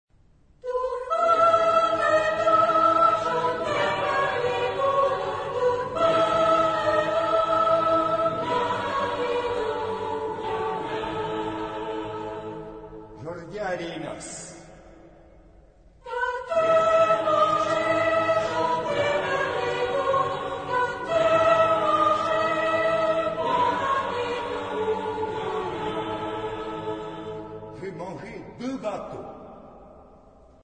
Genre-Style-Form: Secular ; Popular ; Song with repetition
Type of Choir: SATB  (4 mixed voices )
Tonality: G major ; D minor
Origin: Aunis (F) ; Saintonge (F)